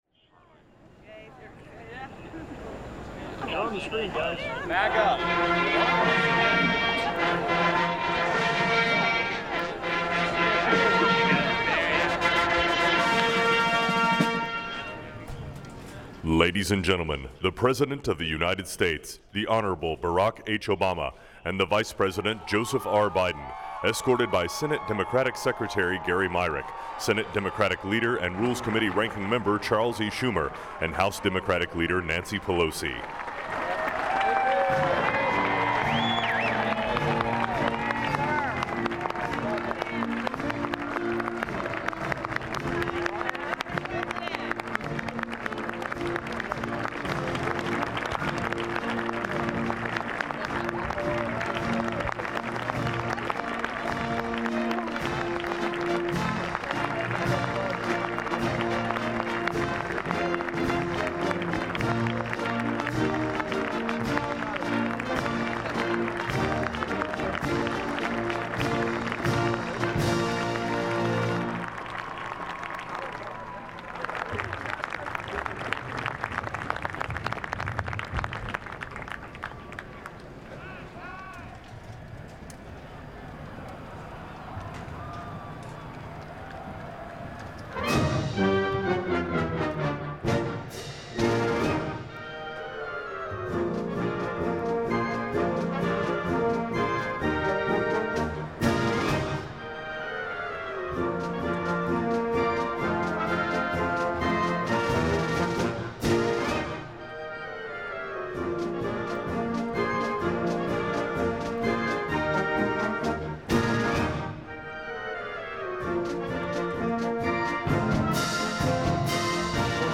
Cardinal Timothy Dolan offers the invocation.
The Missouri State University Chorale sings "Now we belong". Senator Charles Schumer (D-NY) talks about the peaceful transfer of power, the Constitution, and the rule of law.
The Mormon Tabernacle Choir sings "America the beautiful". President elect Donald Trump recites the oath of office administered by Supreme Court Chief Justice John Roberts.
Jackie Evancho sings "The star spangled banner".